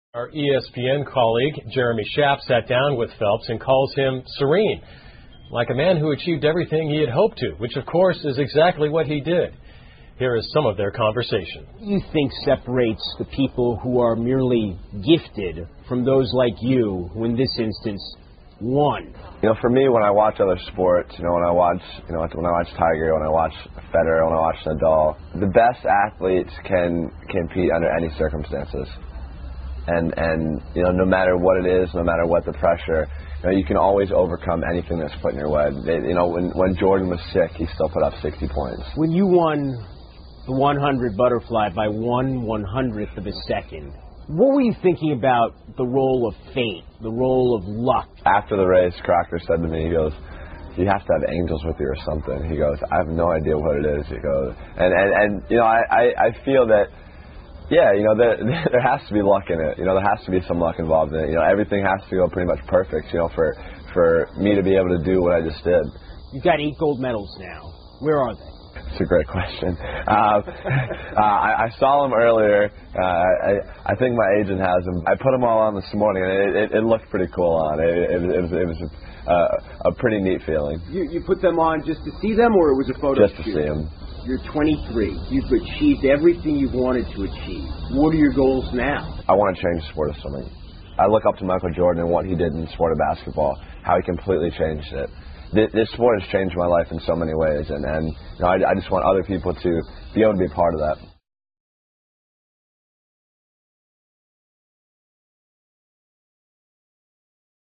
西部落：菲尔普斯访谈 听力文件下载—在线英语听力室